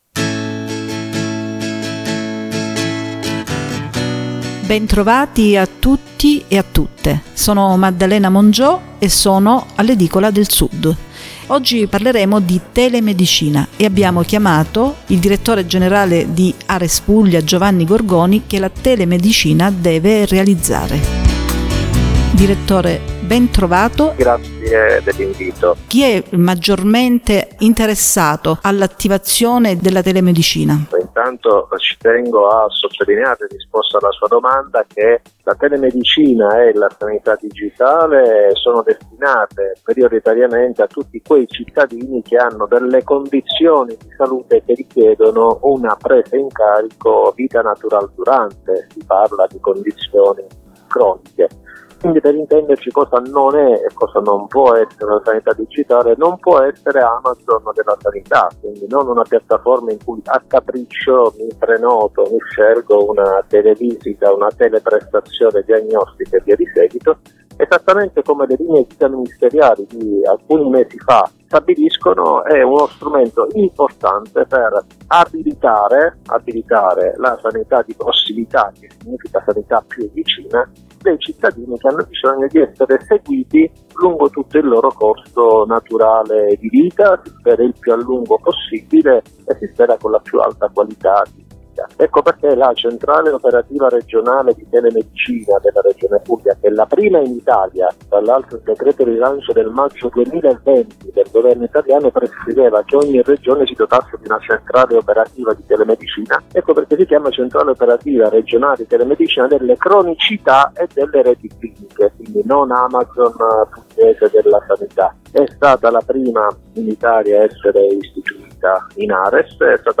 Intervista al direttore generale di Ares Puglia.